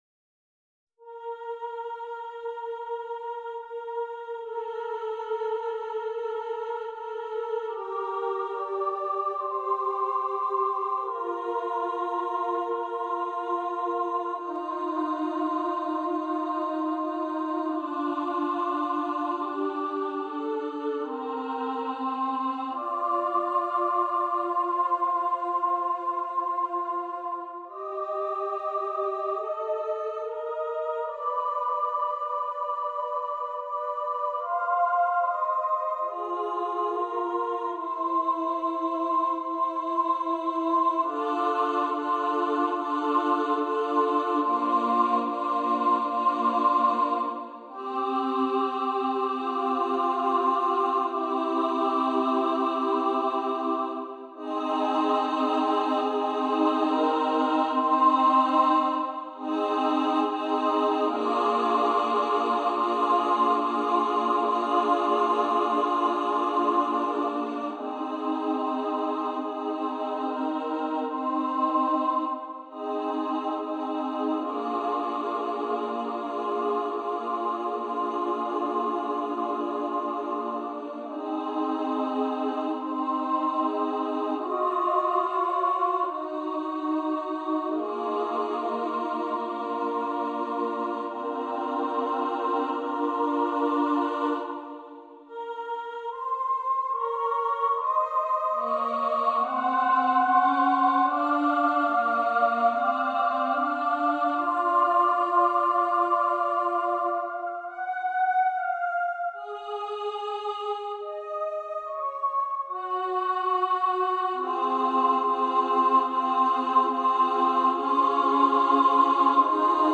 SSA a cappella Sacred